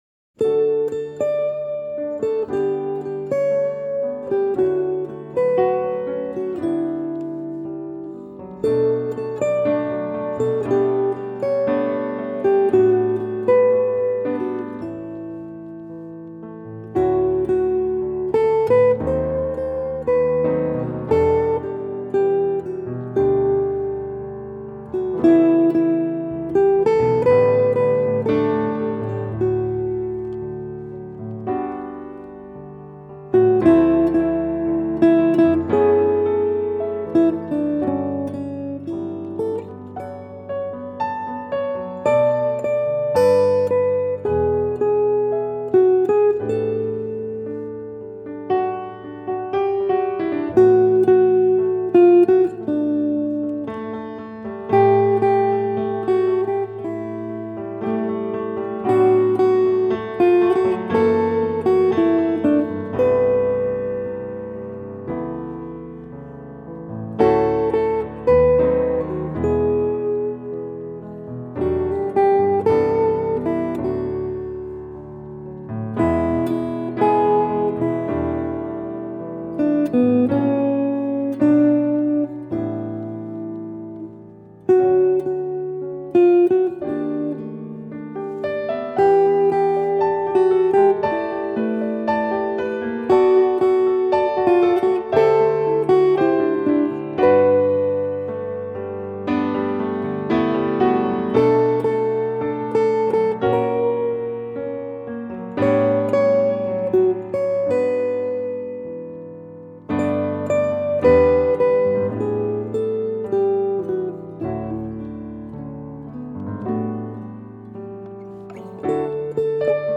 gitarr
piano